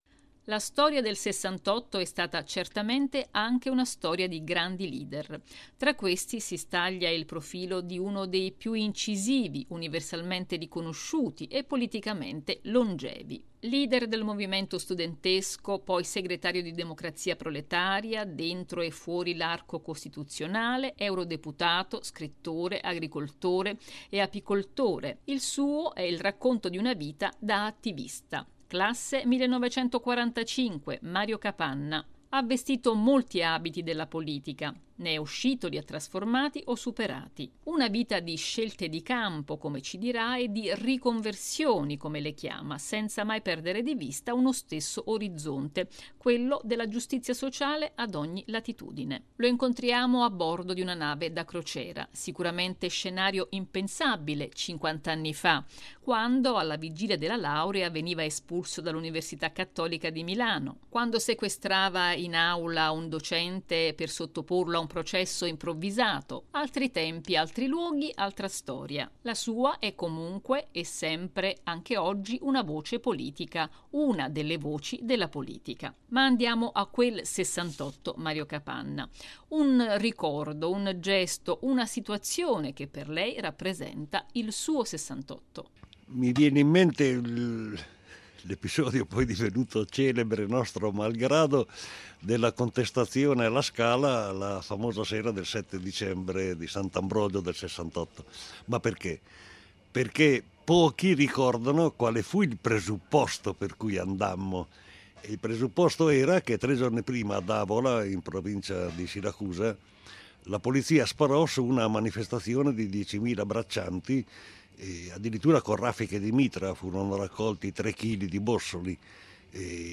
Dalla descrizione di un ‘precariato mondiale’ da combattere all’appello a rompere un ‘silenzio complice’: in una conversazione a bordo di una nave da crociera la sua voce politica per l’oggi.